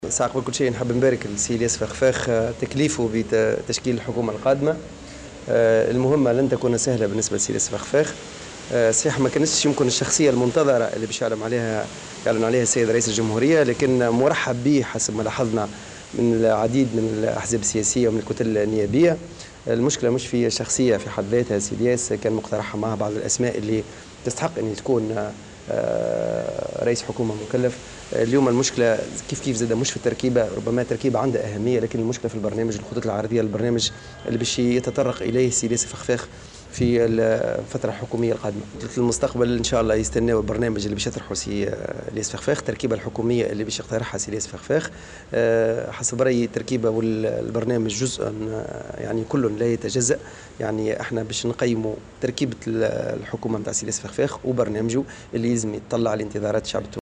وأضاف في تصريح اليوم لمراسلة "الجوهرة أف أم" أن تصويت كتلة المستقبل من عدمه لحكومة الفخفاخ يبقى رهين تركيبة هذه الحكومة والخطوط العريضة لبرنامجها، وفق قوله.